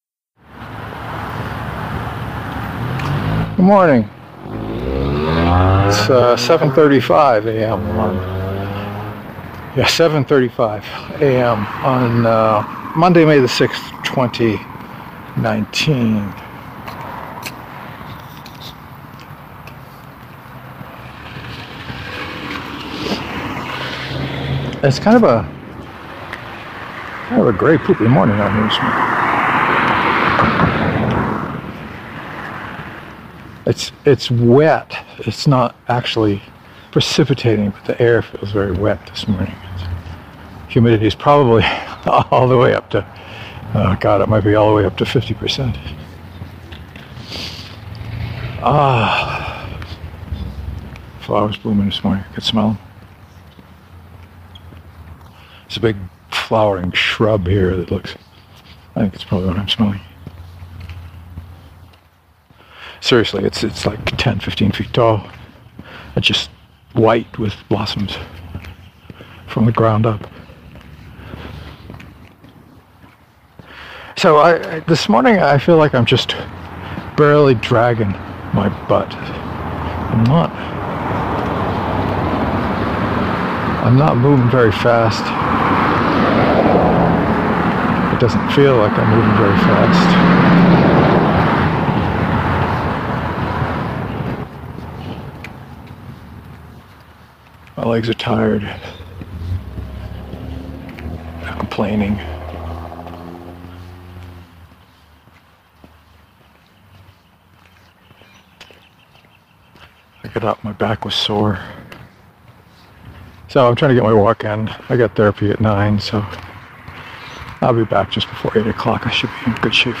I barely hobbled along this morning so you got more audio of my boots than normal.